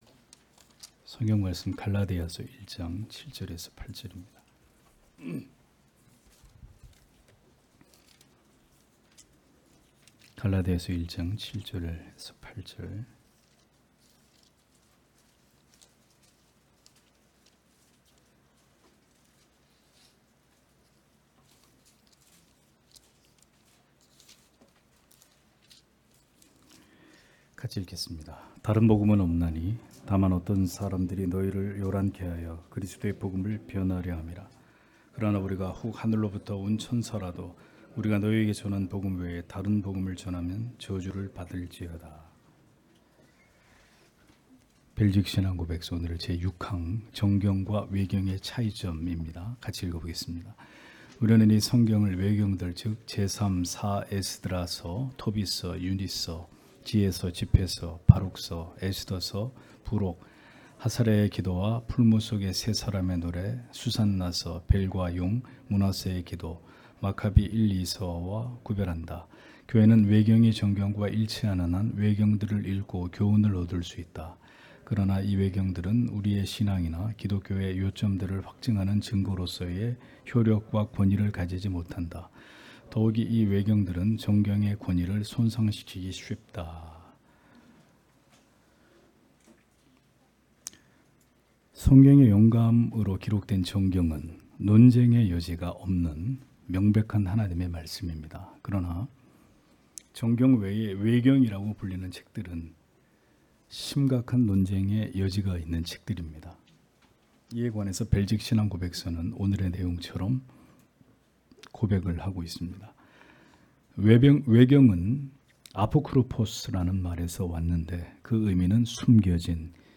주일오후예배 - [벨직 신앙고백서 해설 6] 제6항 정경과 외경의 차이점 (갈라디아서 1장 7-8절)